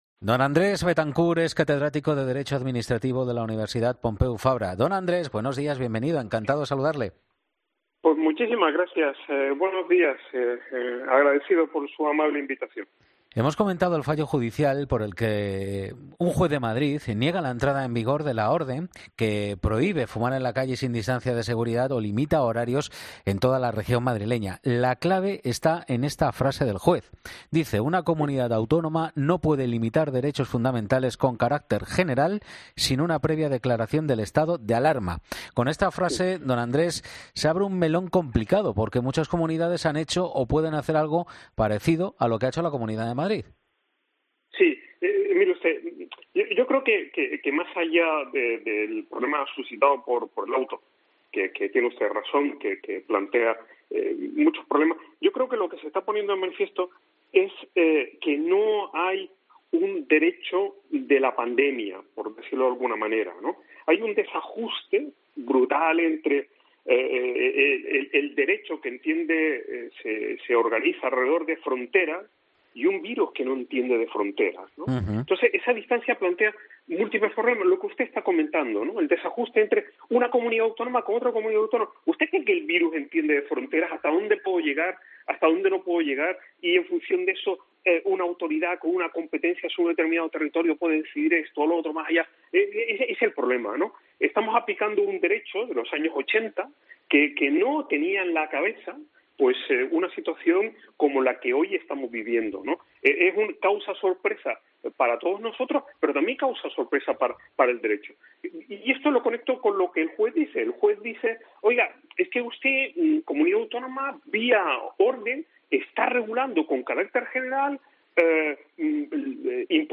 Catedrático, en COPE: "Estamos intentando administrar jurídicamente un virus que no entiende de fronteras"